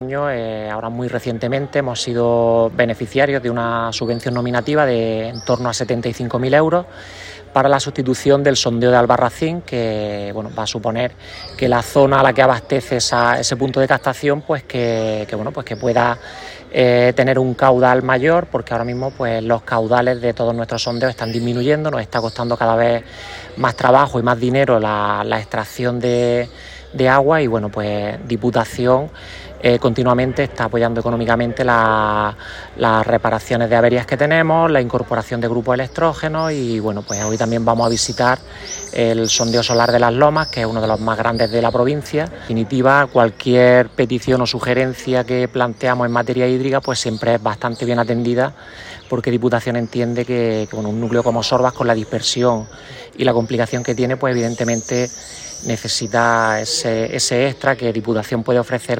11-07__dipu_sondeo__alcalde_de_sorbas.mp3